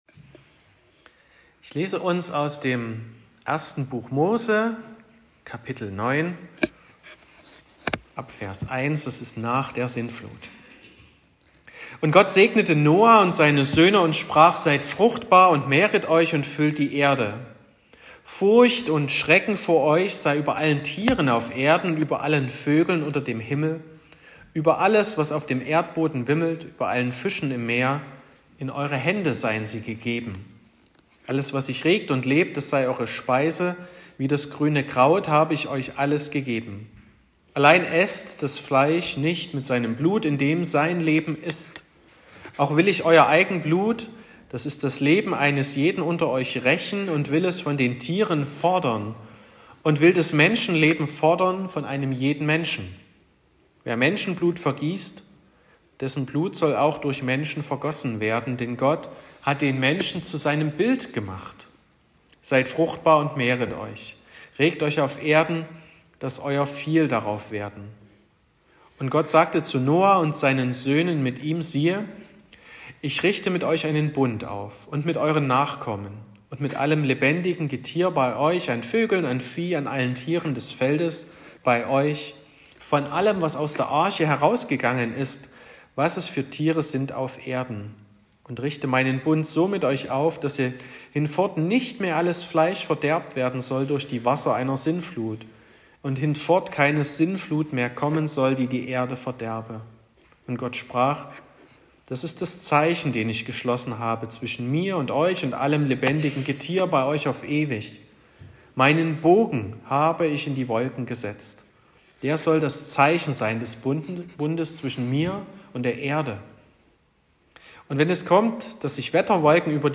22.09.2024 – Gottesdienst
Predigt und Aufzeichnungen